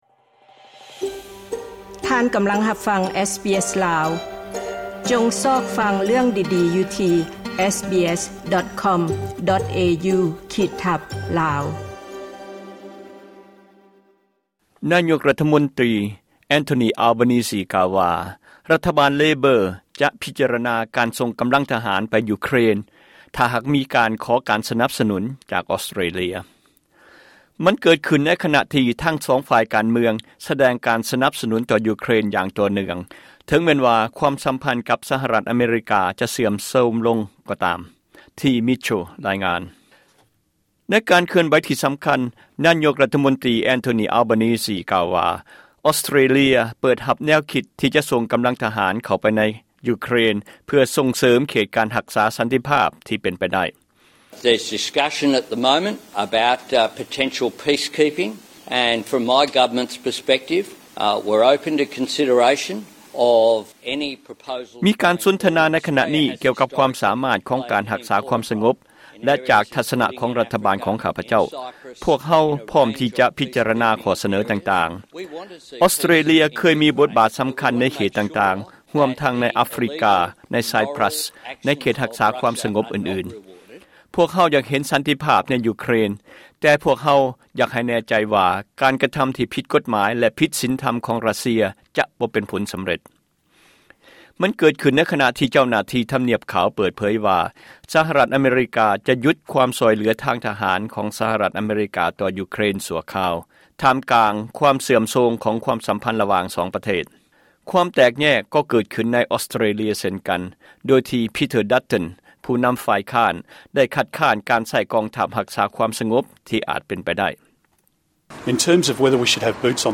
ລາຍງານ.